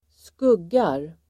Uttal: [²sk'ug:ar]